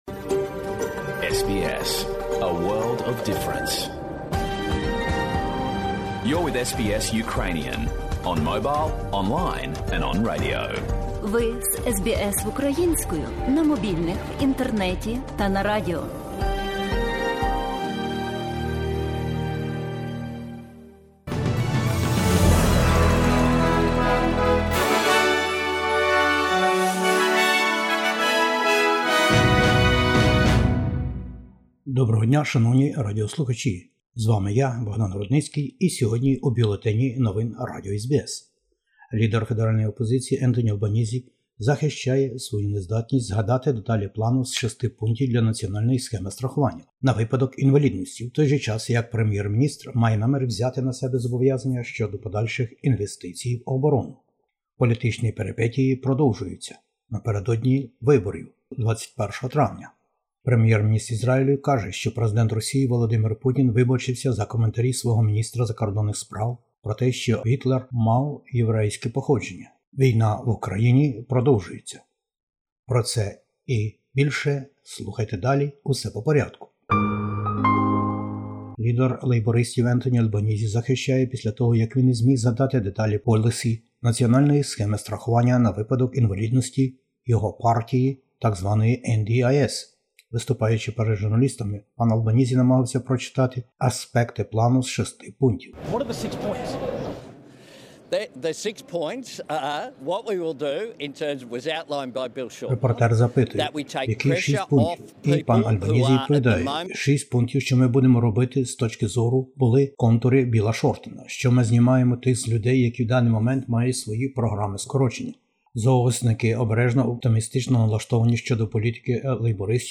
Бюлетень SBS новин українською мовою. Федеральні вибори-2022 - політичні периптії лейбористів та коаліції: страхування NDIS та інвестиції в оборонну галузь.